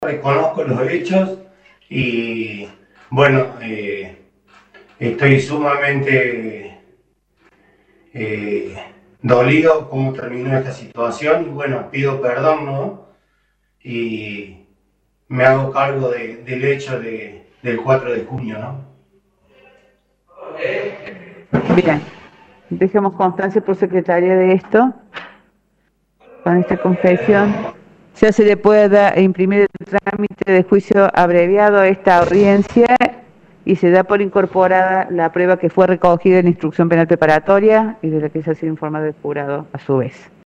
Audio del juicio: